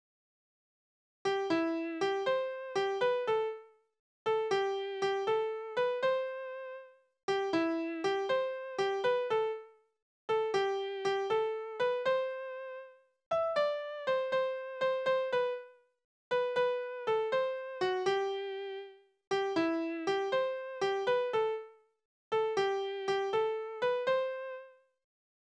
Necklieder: Müller und Schornsteinfeger als Rivalen
Tonart: C-Dur
Taktart: 6/8
Tonumfang: kleine Septime
Besetzung: vokal